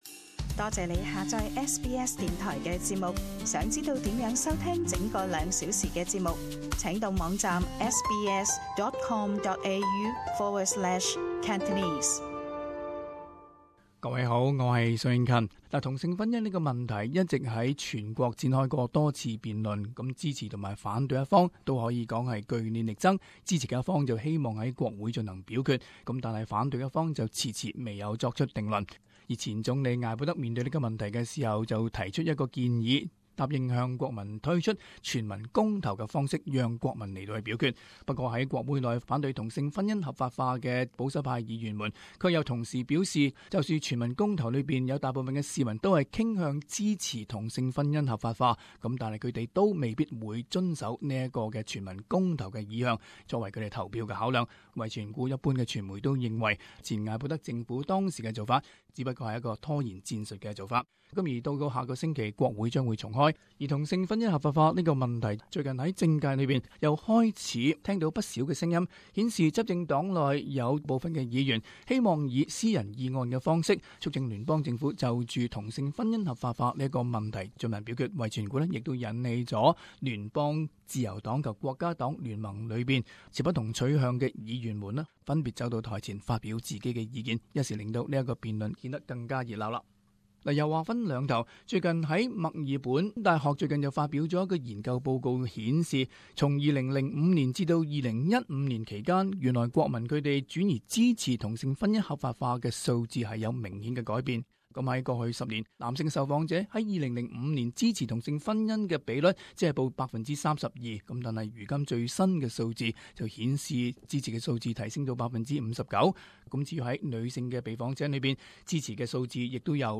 【時事報導】同性婚姻合法化的辯論轉趨熾熱